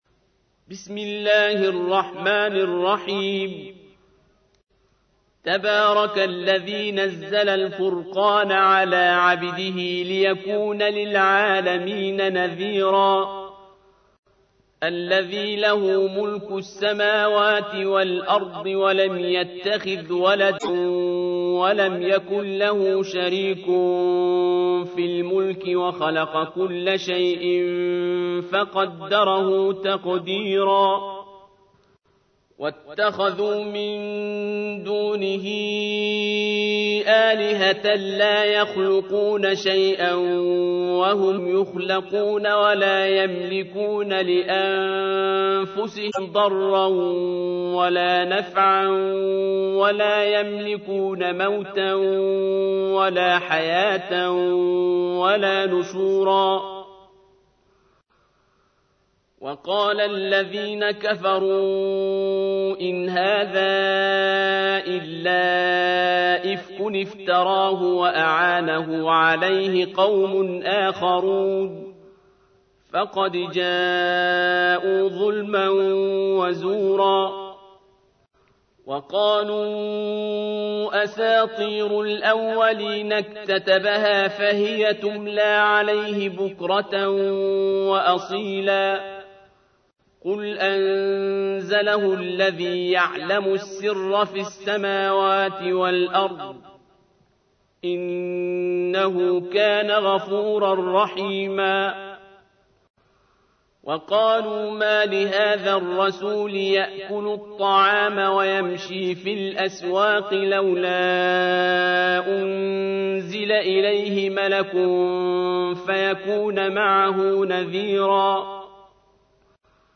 تحميل : 25. سورة الفرقان / القارئ عبد الباسط عبد الصمد / القرآن الكريم / موقع يا حسين